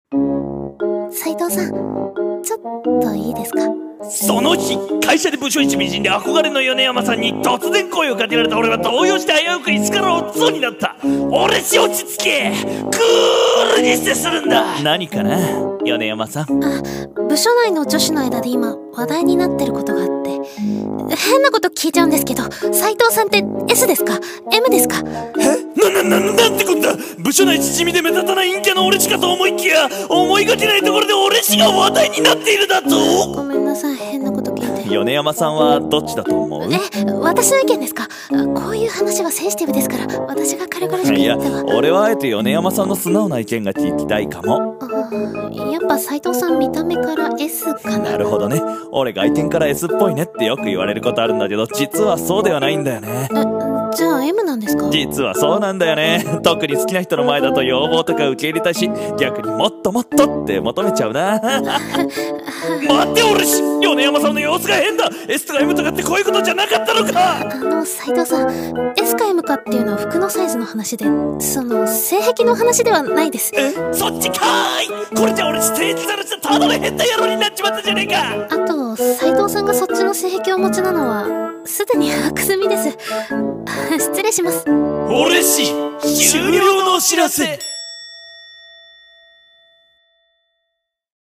【声劇】陰キャな俺氏と噂話